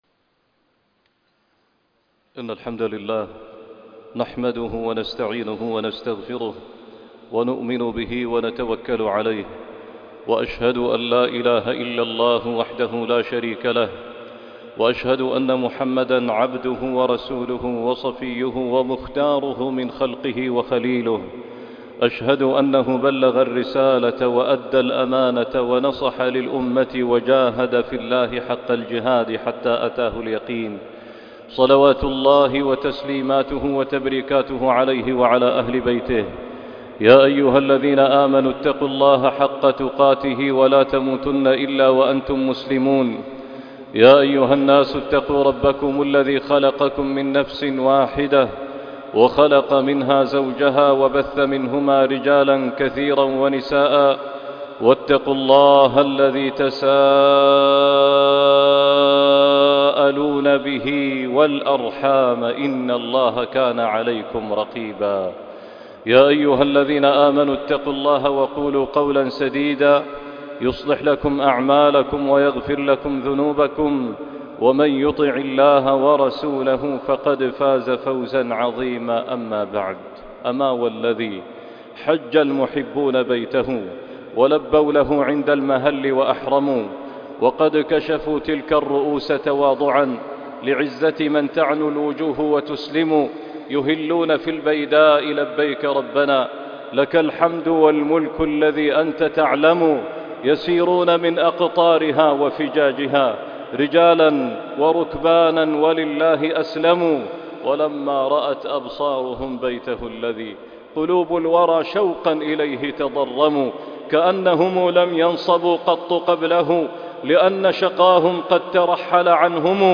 سيد الأيام خطبة الجمعة